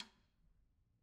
Snare2-taps_v1_rr2_Sum.wav